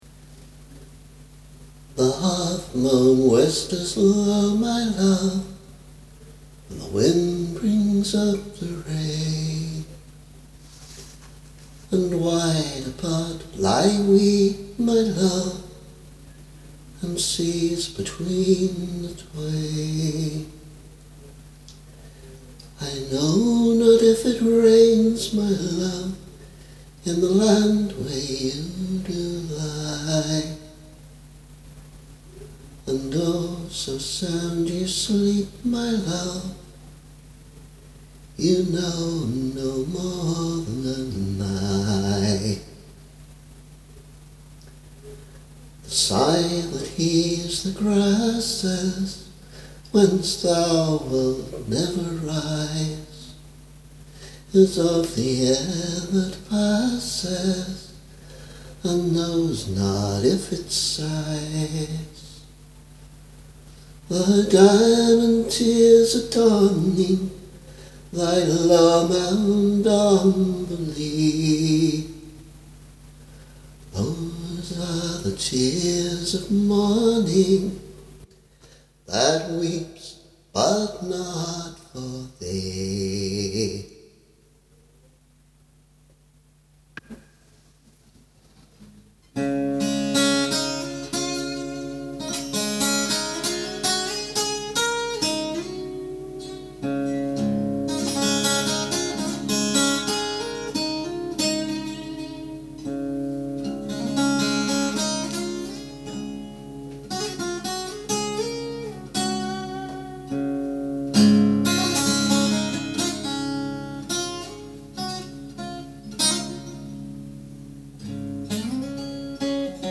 A suite rather than an individual song. At the moment it combines a version of Tears of Morning (a setting of two lyrics from Housman’s Last Poems); the air-like guitar piece that would be the basis of a guitar arrangement for that setting and a bridge to the next section; this next section would be my song Sea Fret (here represented by just two verses using a different guitar arrangement to fit in with the first sections, and incorporating some bits from an old guitar piece of mine called Bluebert); and finally, an instrumental interpretation of She Moved Through The Fair based on Davy Graham’s arrangement from the 1960s.
There’s a lot of work to do on this (and the vocals illustrate clearly why you shouldn’t sing just after eating pizza): here, I’m just working out some ideas for an overall structure.